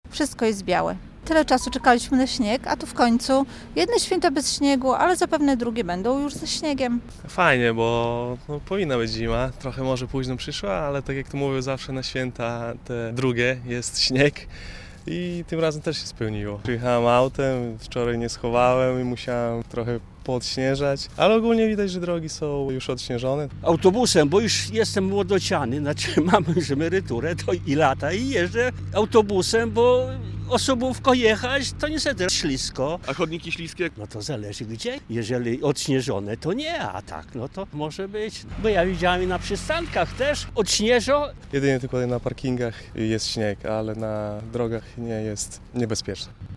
Mimo wymagających warunków drogowych, taka aura cieszy spotkanych przez nas białostoczan.